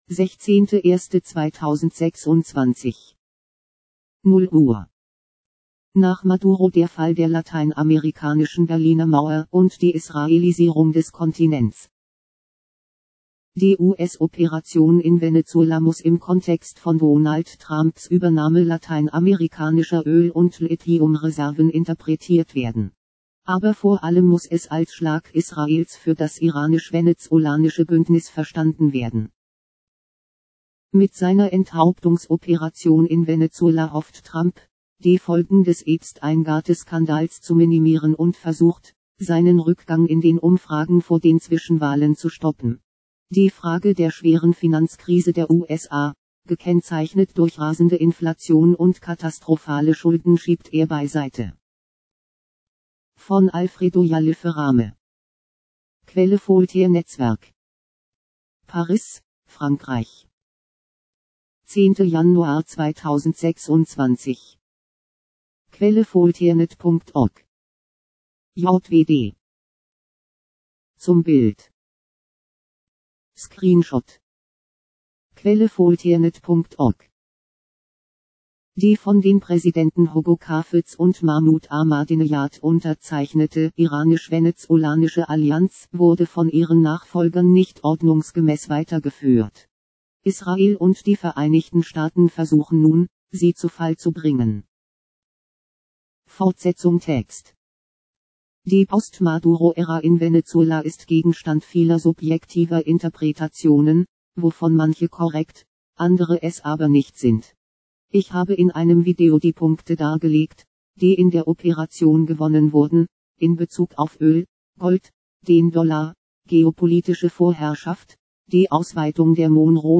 ..vorlesen | Popupfenster öffnen mp3 |  erzeugt mit Pediaphone | JWD